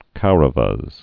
(kourə-vəz)